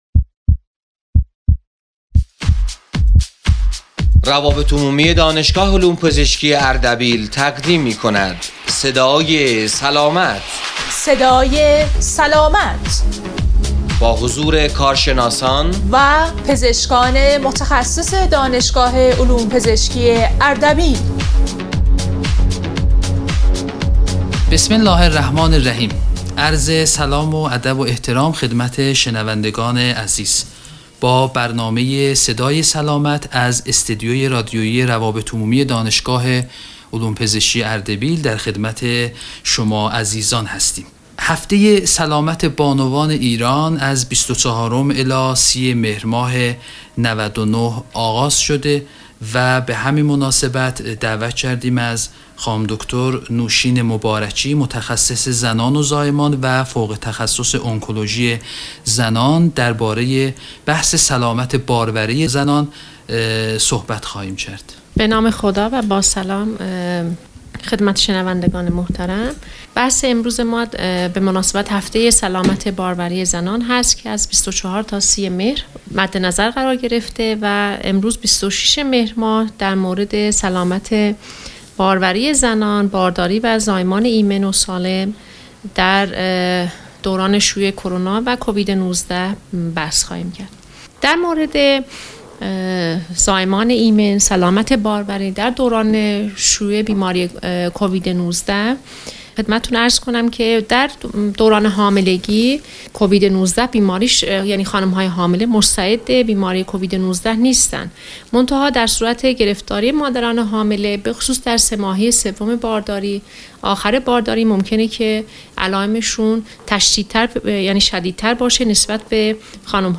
برنامه رادیویی صدای سلامت